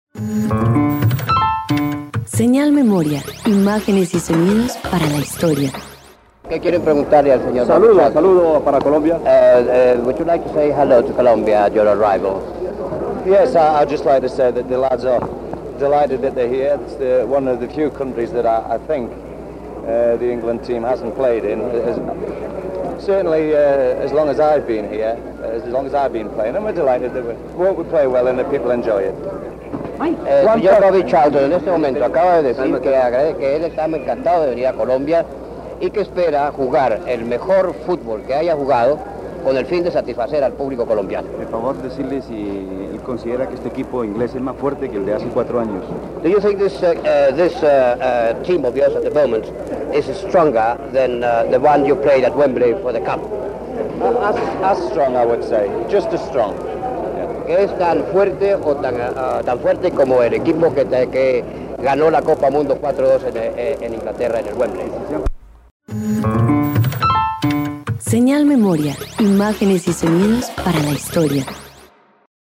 En esta emisión se presenta una rueda de prensa a Bobby Charlton, jugador de la Selección de Fútbol de Inglaterra